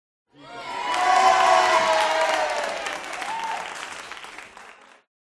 Звуки аудитории, толпы
Студийная публика, среднего размера, радостные крики и аплодисменты 28